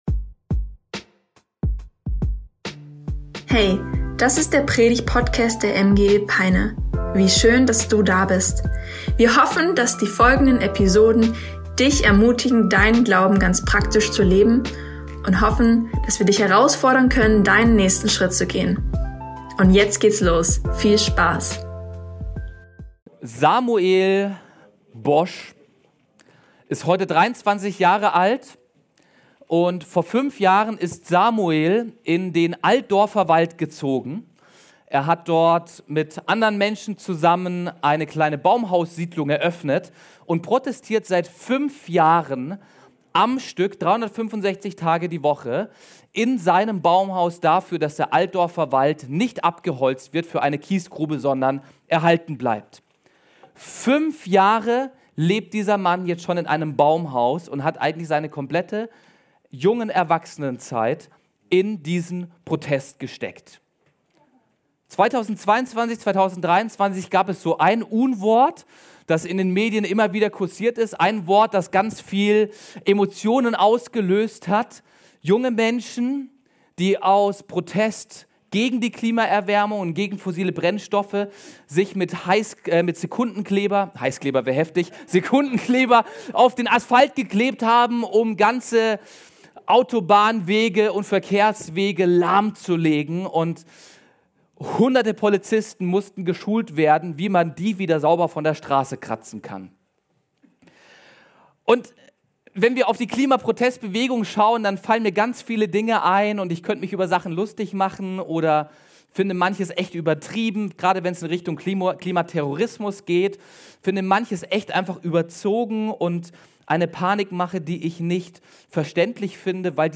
Schau oder hör Dir unsere neueste Predigt an - MGE Peine